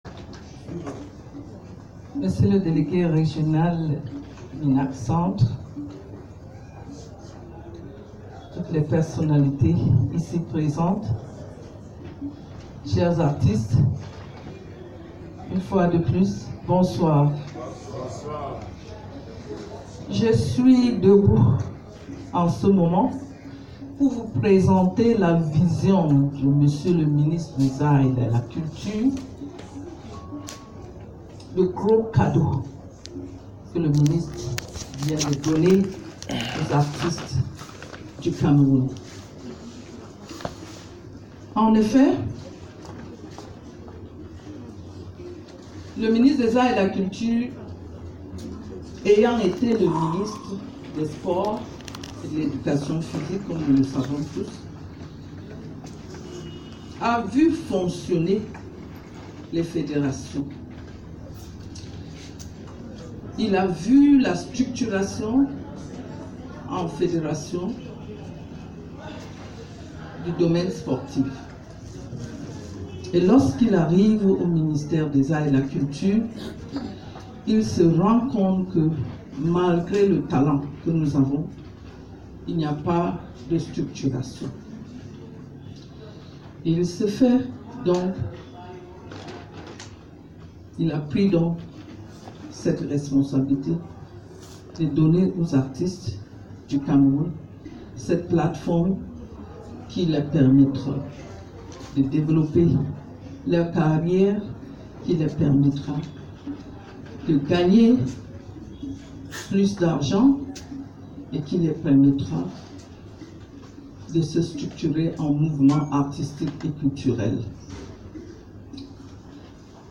Structuration du pôle Art musical : Cérémonie de lancement des activités dans la région du Centre